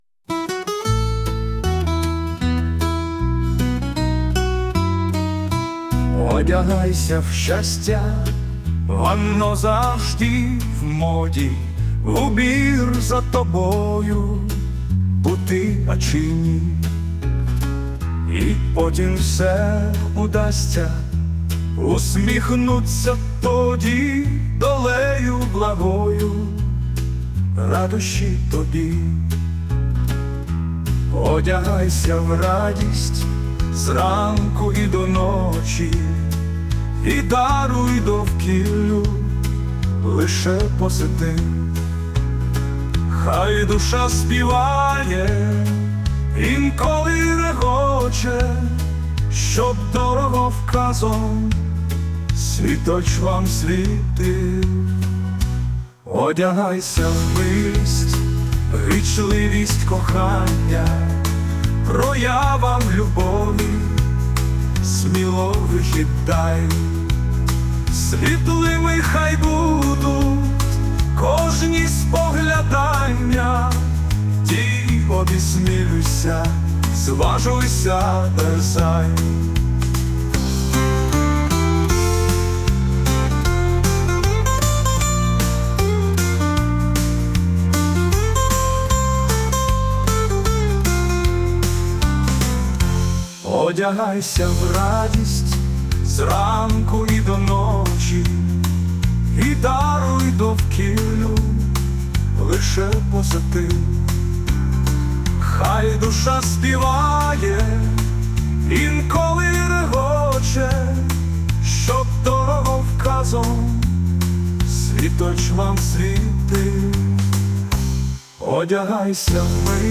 Музика та спів - ШІ
/ ШІ  використано у створенні музики пісні.